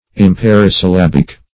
Search Result for " imparisyllabic" : The Collaborative International Dictionary of English v.0.48: Imparisyllabic \Im*par"i*syl*lab"ic\, a. [L. impar unequal + E. syllabic: cf. F. imparisyllabique.] (Gram.) Not consisting of an equal number of syllables; as, an imparisyllabic noun, one which has not the same number of syllables in all the cases; as, lapis, lapidis; mens, mentis.